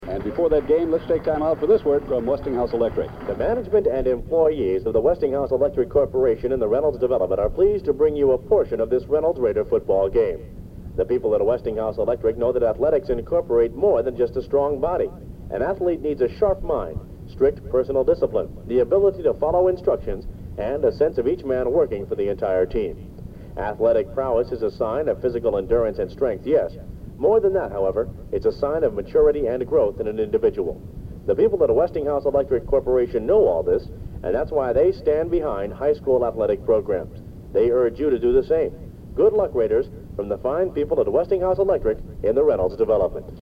* Old Radio Commercials -